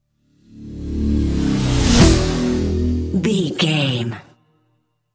Ionian/Major
Fast
synthesiser
drum machine